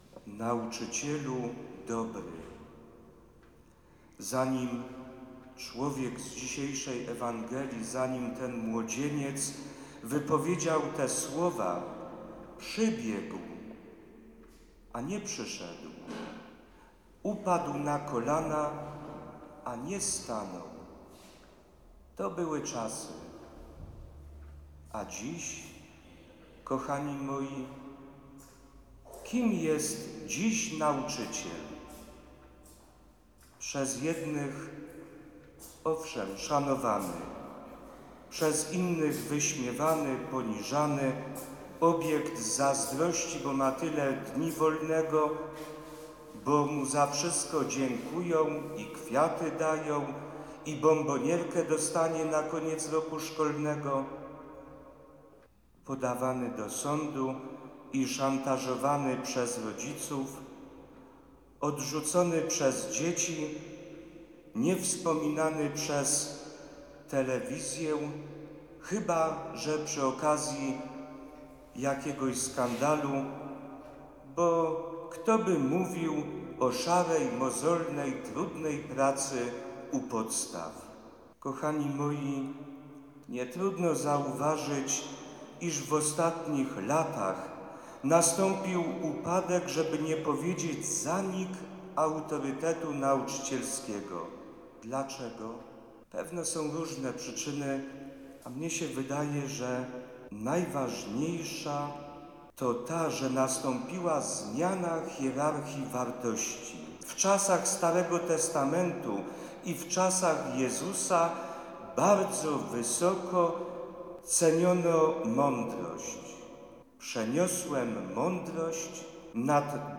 14 października w kościele parafialnym p.w. Matki Bożej Śnieżnej zgromadzili się na wspólnej modlitwie pedagodzy; nauczyciele, wychowawcy, katecheci i pracownicy związani z oświatą.